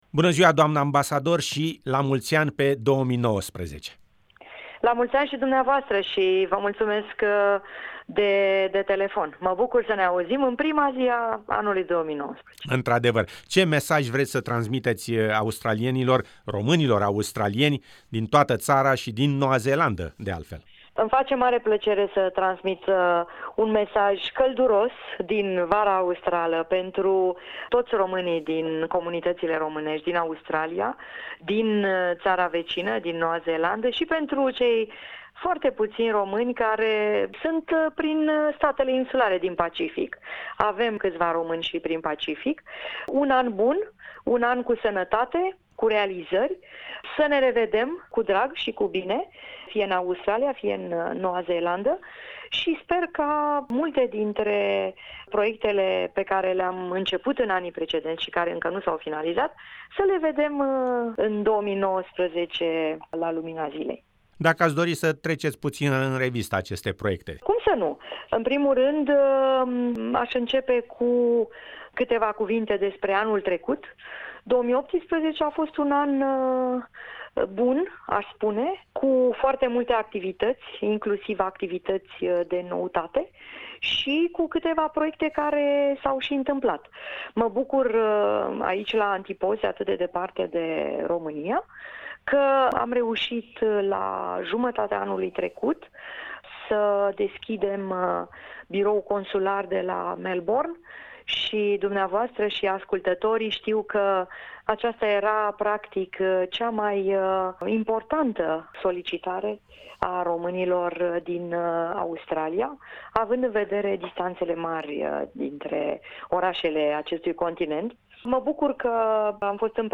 Mesajul de Anul Nou pentru toti romanii din Australia, Noua Zeelanda si insulele din apropiere, din partea Excelentei Sale, Nineta Barbulescu, Ambasadorul Romaniei in Australia si Noua Zeelanda.